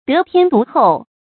得天獨厚 注音： ㄉㄜˊ ㄊㄧㄢ ㄉㄨˊ ㄏㄡˋ 讀音讀法： 意思解釋： 天：天生；自然的；獨：獨特；厚：優厚。